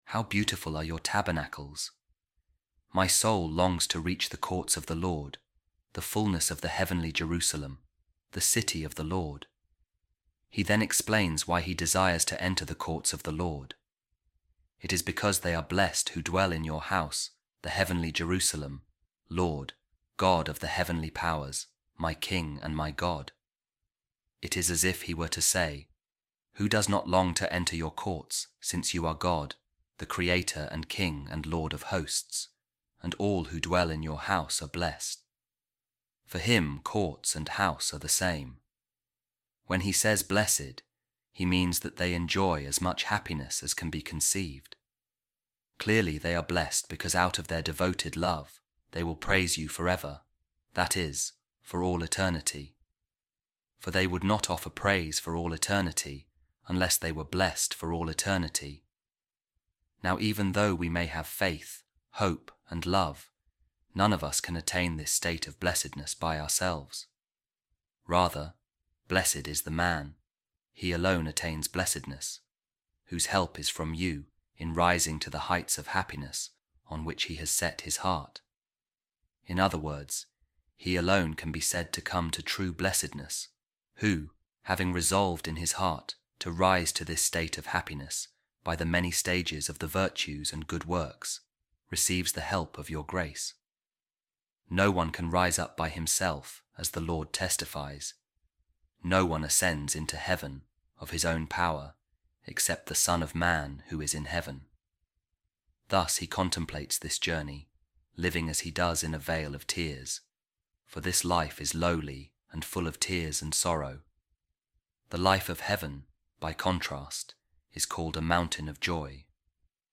A Reading From The Commentary Of Saint Bruno On the Psalms | If I Forget You, O Jerusalem